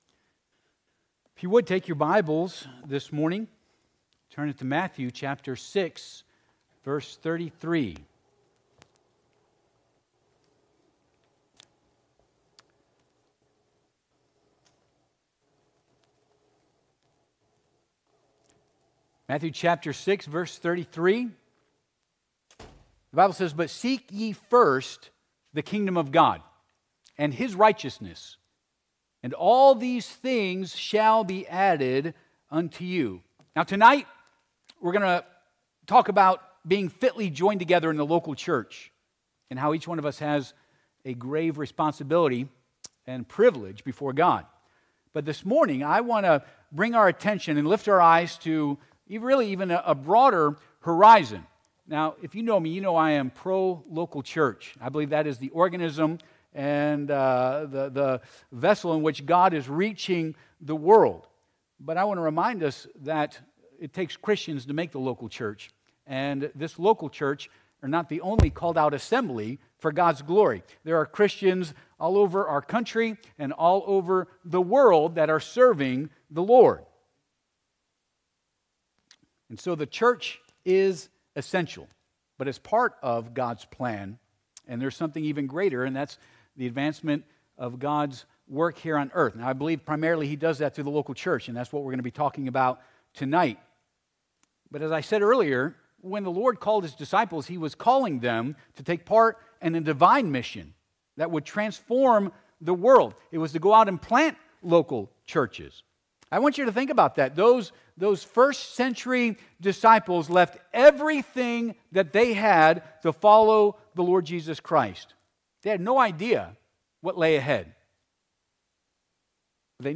Matt. 6:33 Service Type: Sunday AM « The Final Days of Joshua Being Part of Something More